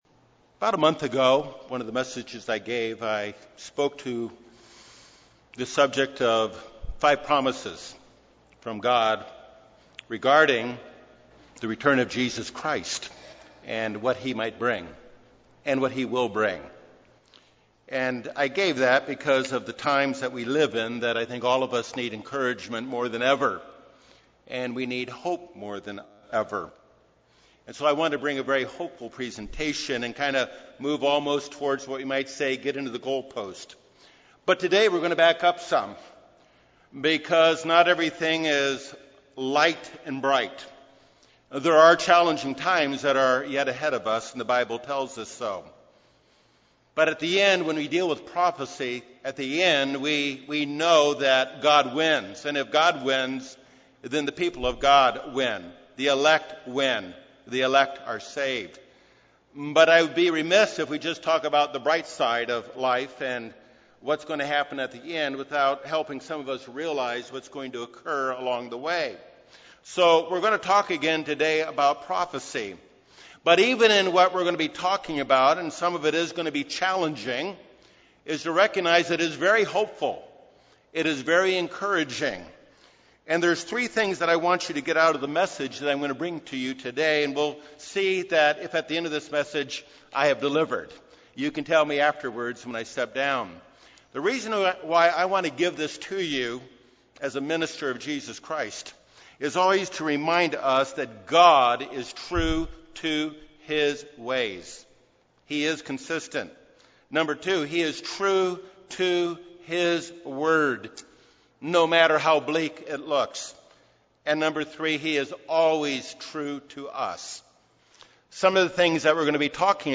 The Bible reveals that two powerful prophets of God will appear at the end time. They are charged with a mission and deliver a message that will draw the attention of the entire world. This sermon explores what the Bible says and does not say about these two individuals.